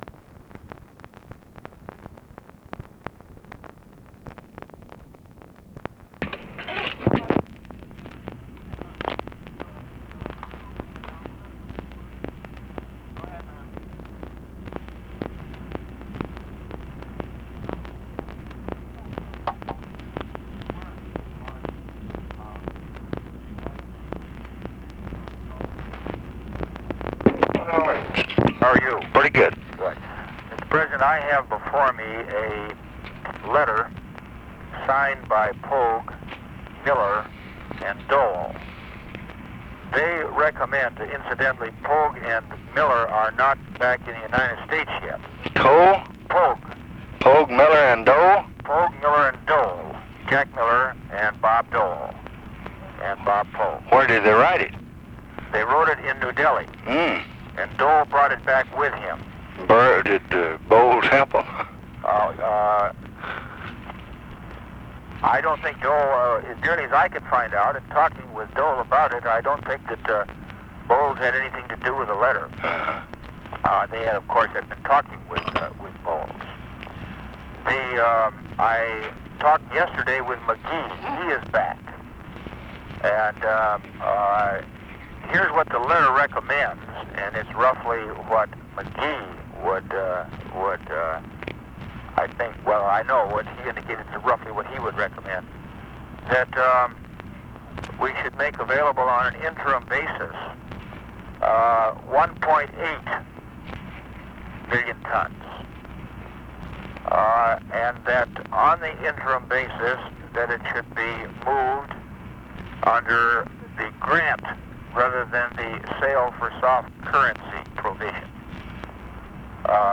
Conversation with ORVILLE FREEMAN, December 22, 1966
Secret White House Tapes